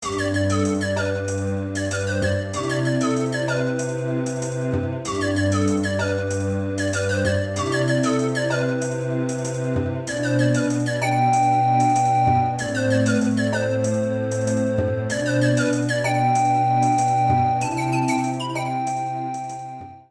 Fair use music sample
Added fade-out at the end